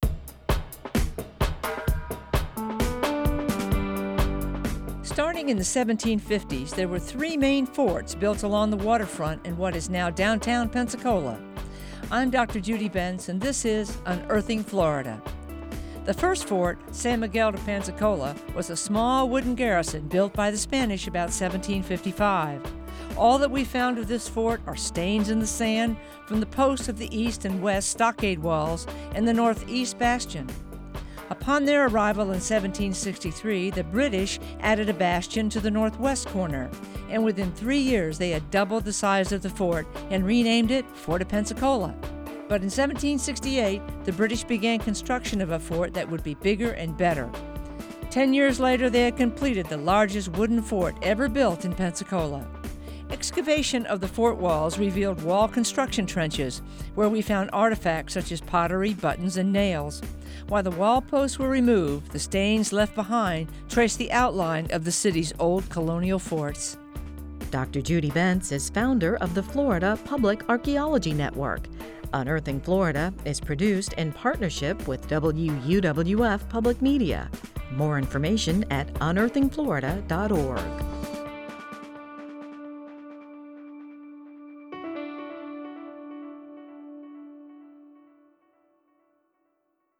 Written, narrated, and produced by the University of West Florida, the Florida Public Archaeology Network, and WUWF Public Media.